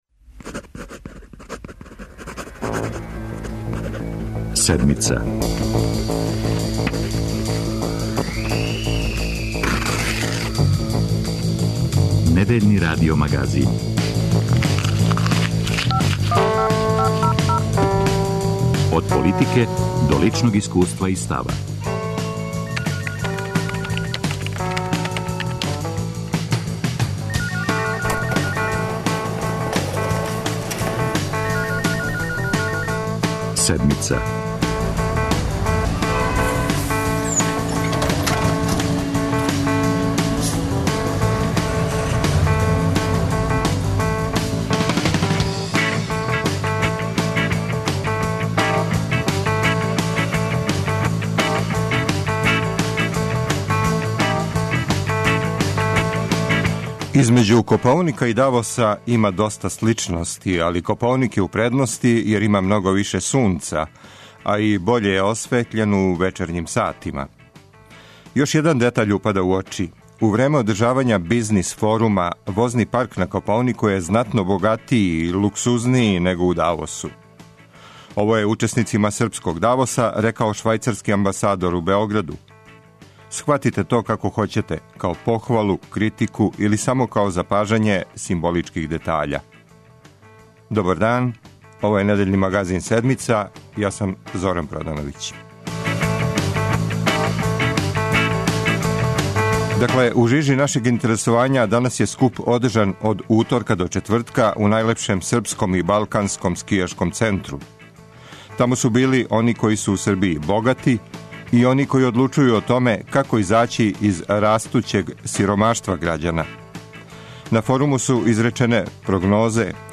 У студију Радио Београда 1 биће угледни економисти који ће тумачити домете обећане економске политике реконструисане Владе Србије.